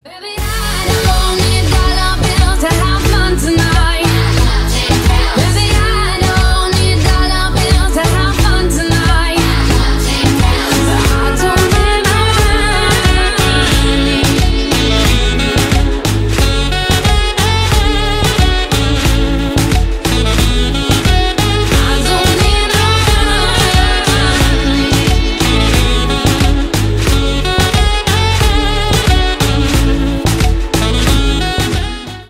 • Качество: 320 kbps, Stereo
Ремикс
Поп Музыка
кавер